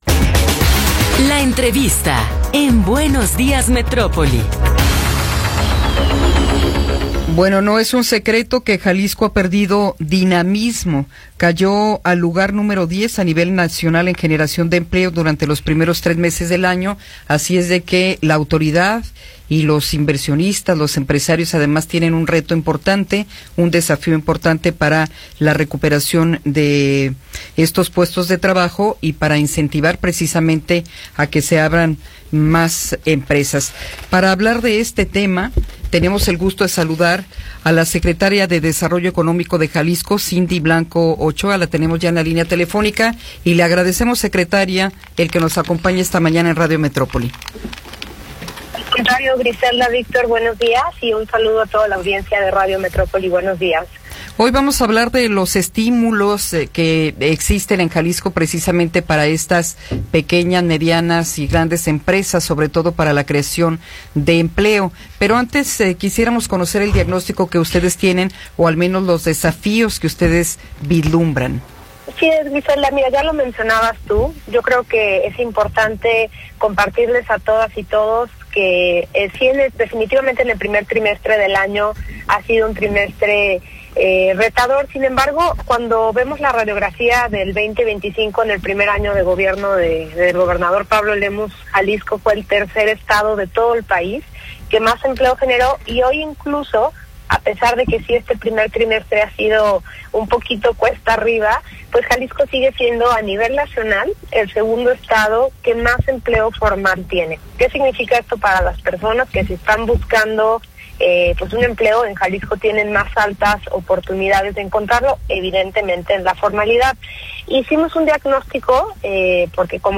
Entrevista con Cindy Blanco Ochoa